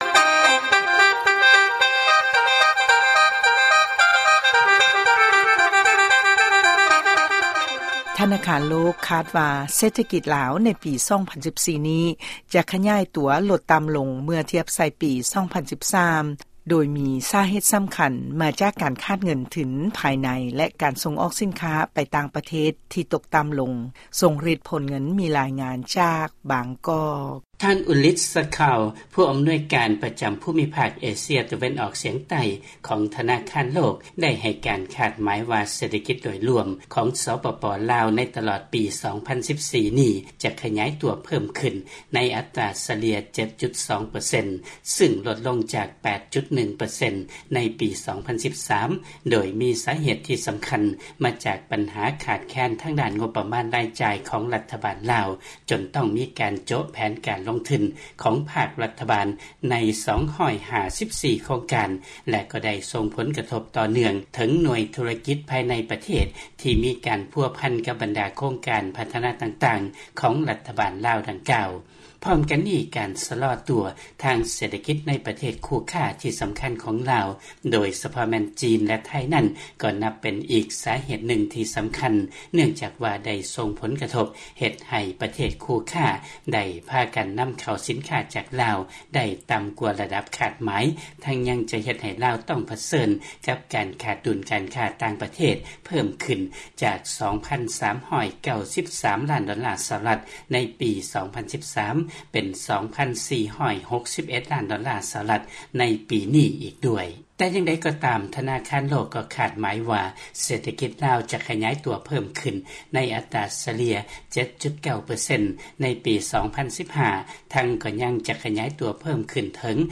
ຟັງລາຍງານ ທະນາຄານໂລກ ຄາດວ່າ ເສດຖະກິດລາວໃນປີ 2014 ນີ້ ຈະຂະຫຍາຍຕົວລົດຕ່ຳລົງ ເມື່ອທຽບໃສ່ປີ 2013.